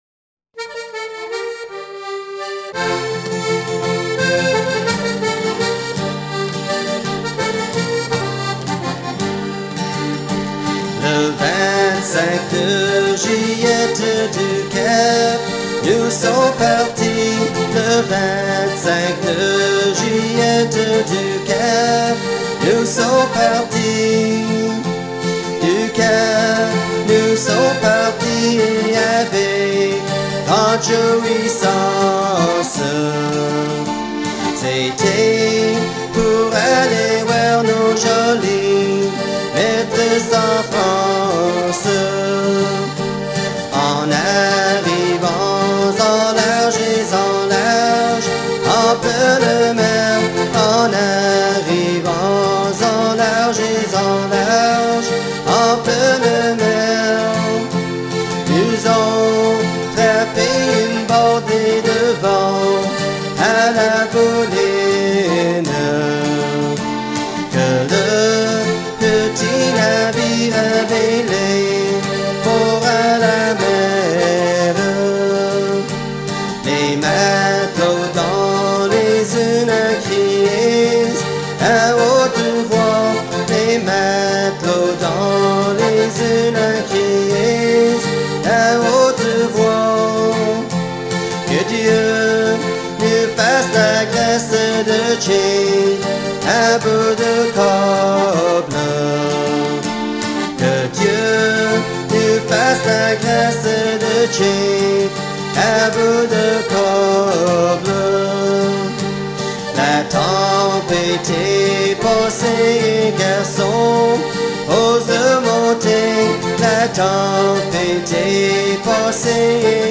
Région ou province Terre-Neuve
Genre strophique
Catégorie Pièce musicale éditée